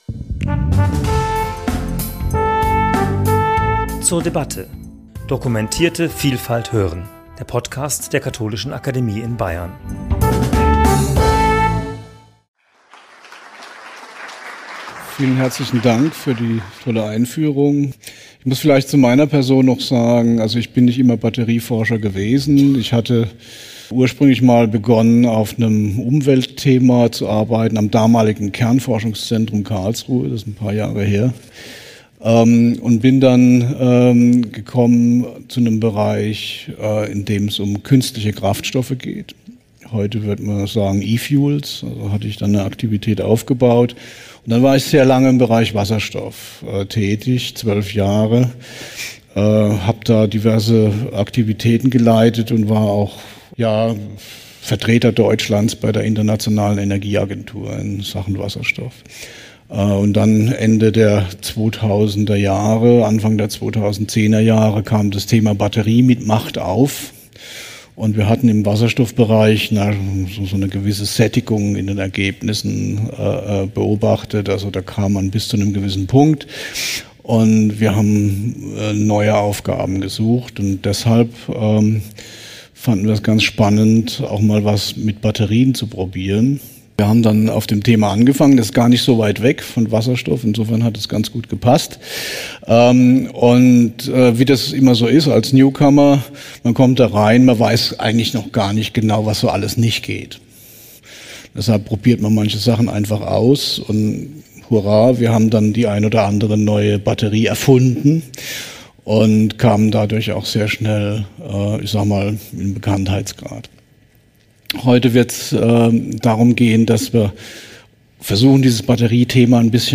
In Kooperation mit dem Deutschen Museum in der Reihe Wissenschaft für jedermann